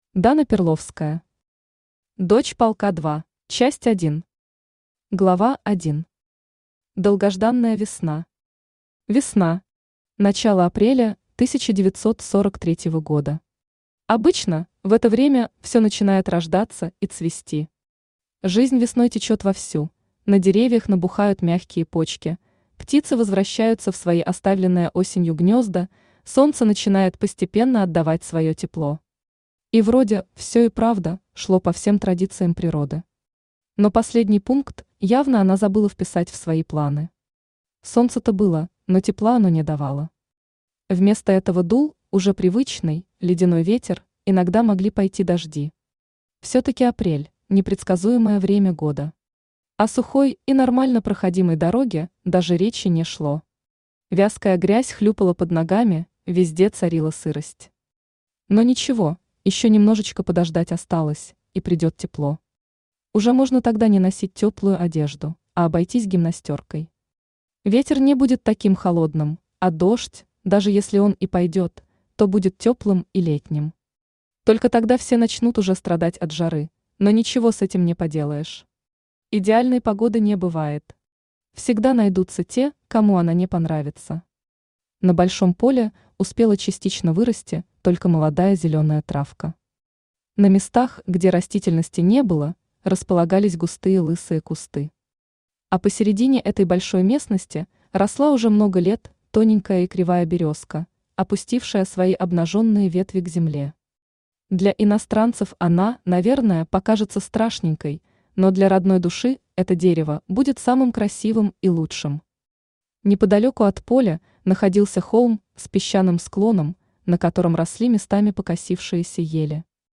Аудиокнига Дочь полка 2 | Библиотека аудиокниг
Aудиокнига Дочь полка 2 Автор Дана Перловская Читает аудиокнигу Авточтец ЛитРес.